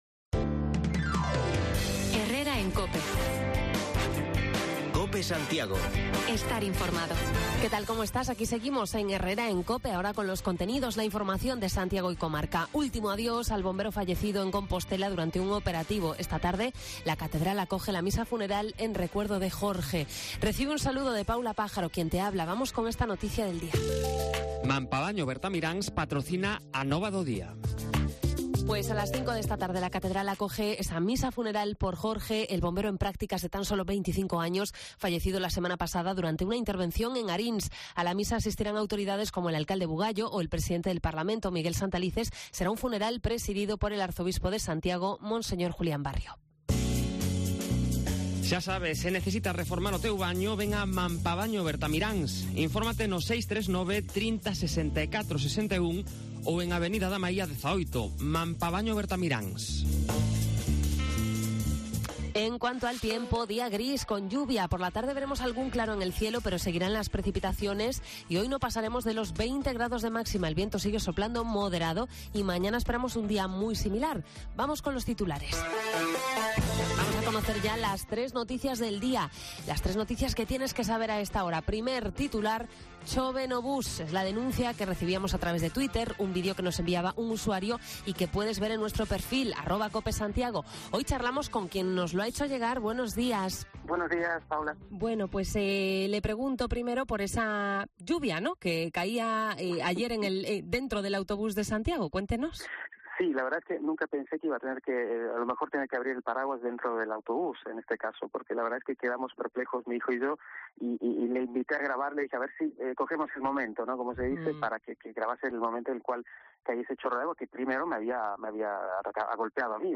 Hablamos de las deficiencias en el transporte urbano en Santiago con un oyente al que ayer le llovió encima dentro de un bus. También nos acercamos hasta el polígono del Tambre para conocer los resultados de los programas de formación de la asociación empresarial de esta zona